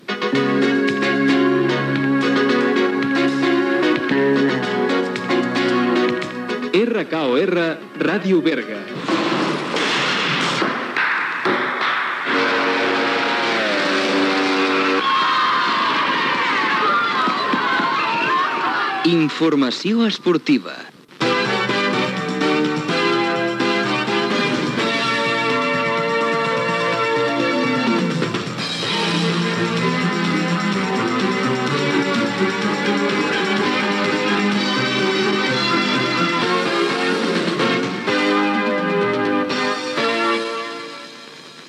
Indicatiu de la informació esportiva de l'esmissora